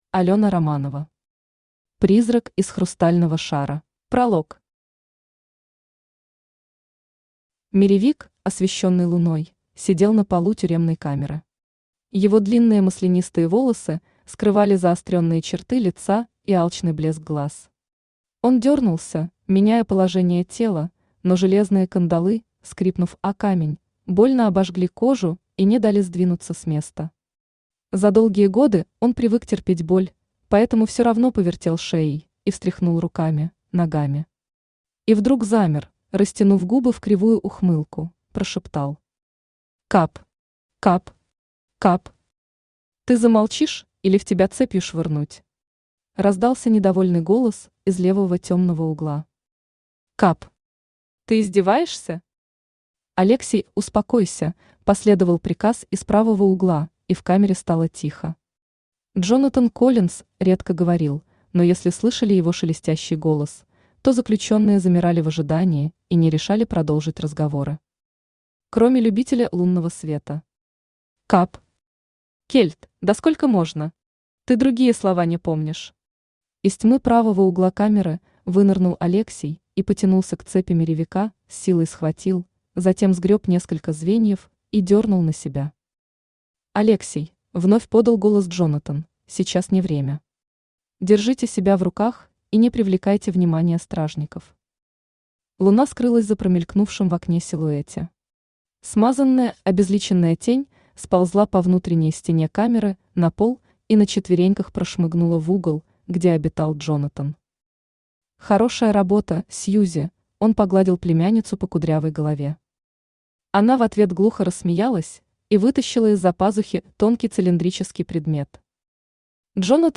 Аудиокнига Призрак из хрустального шара | Библиотека аудиокниг
Aудиокнига Призрак из хрустального шара Автор Алёна Романова Читает аудиокнигу Авточтец ЛитРес.